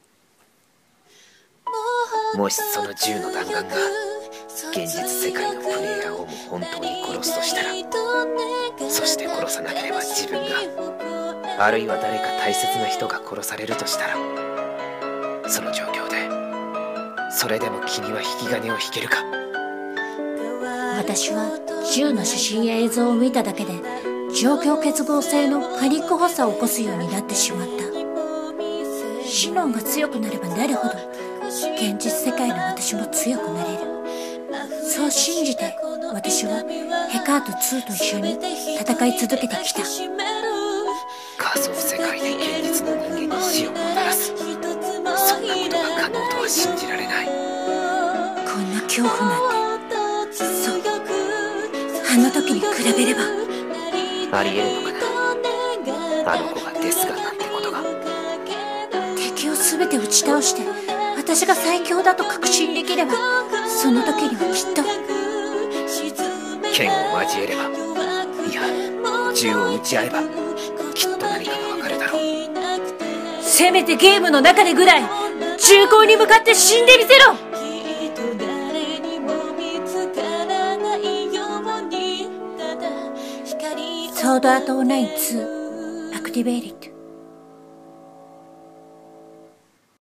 【コラボ声劇】SAOⅡロングPV予告編